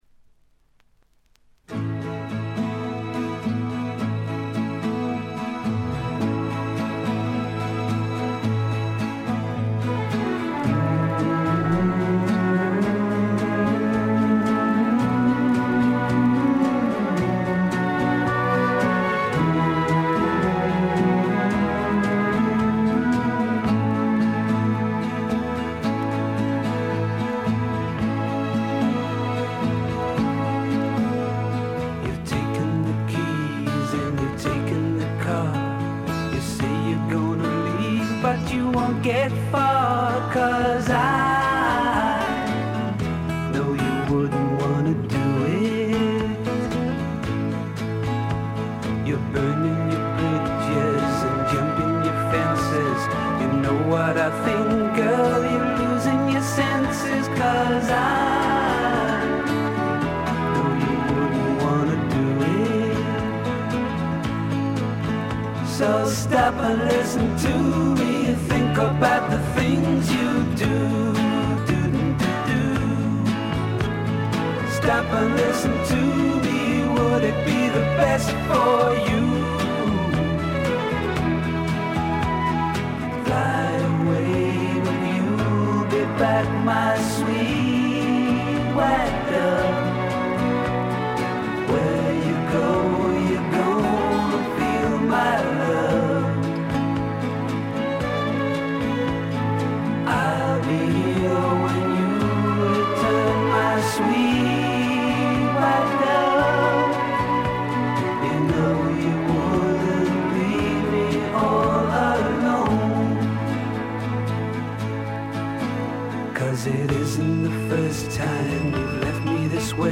ところどころでチリプチ。
ソフトサイケ、ドリーミーポップの名作。
試聴曲は現品からの取り込み音源です。